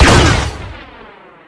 Mw-20 Bryar Pistol 3 - Botón de Efecto Sonoro